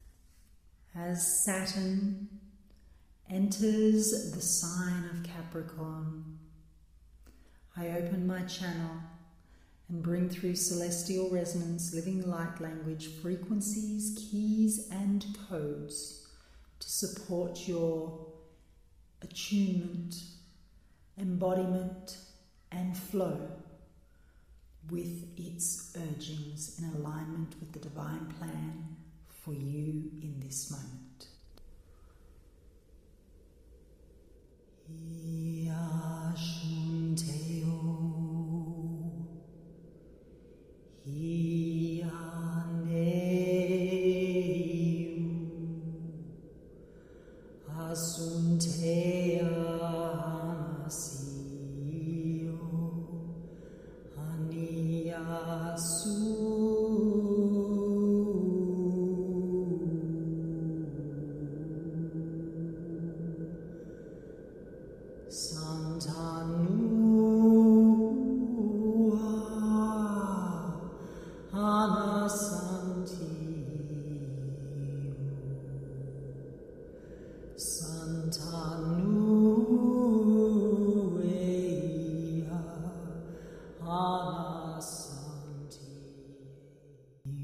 Work with my potent Celestial Resonance Saturn in Capricorn Activation (Healing Song) BELOW!
Sharing a 90-second sample of this 6-minute transmission